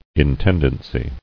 [in·ten·dan·cy]